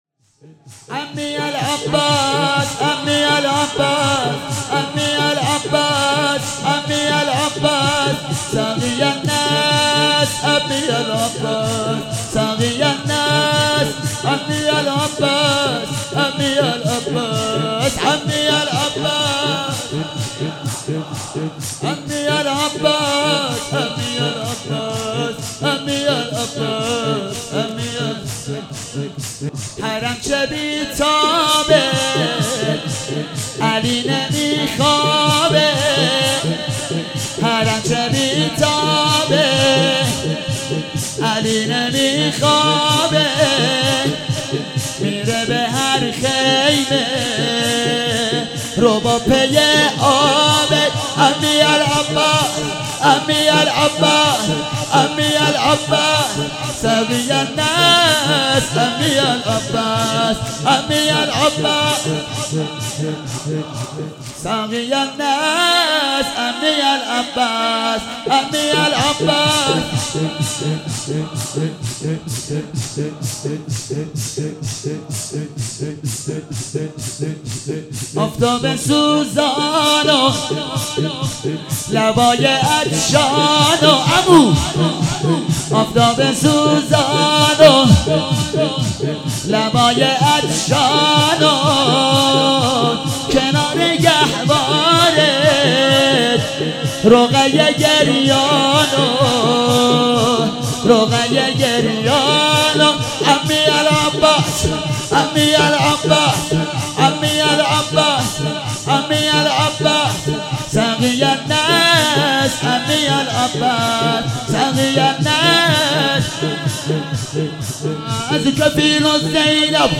محرم96